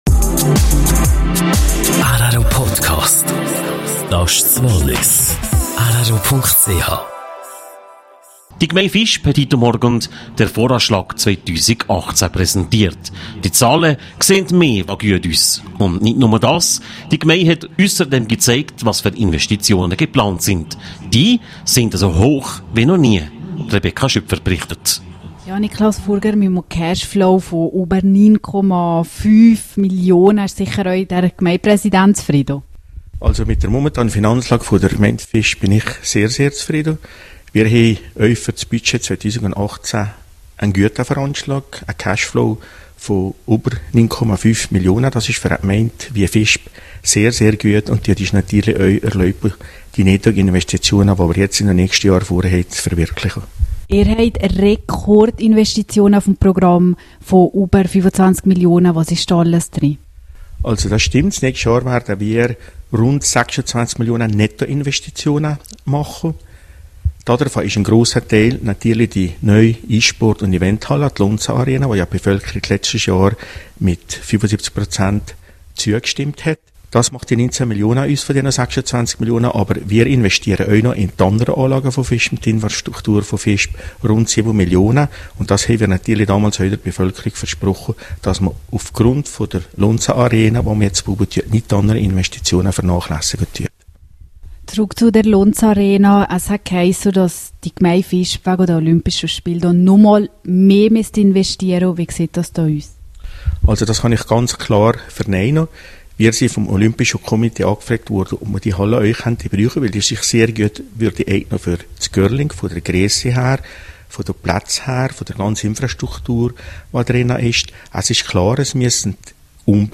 Der Visper Gemeindepräsident Niklaus Furger im Interview zum Voranschlag 2018.